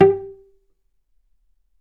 vc_pz-G4-ff.AIF